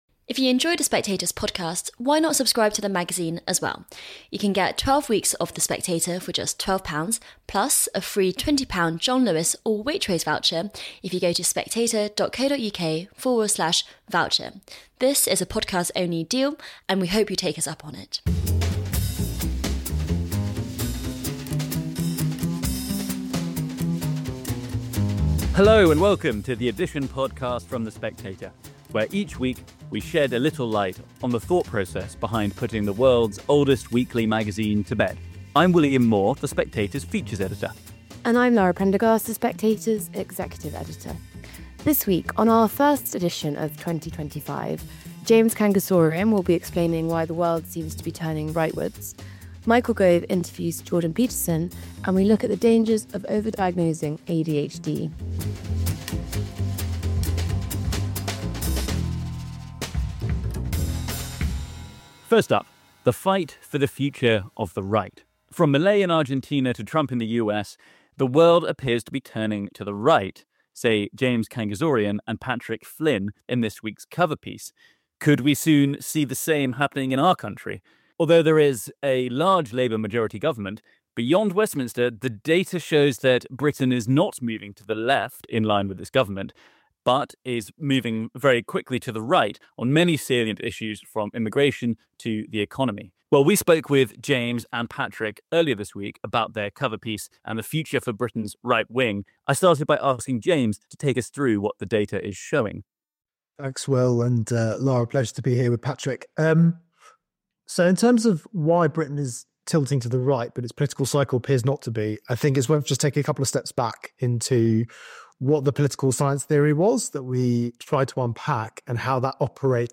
The Edition: the West's right turn, Michael Gove interviews Jordan Peterson & the ADHD trap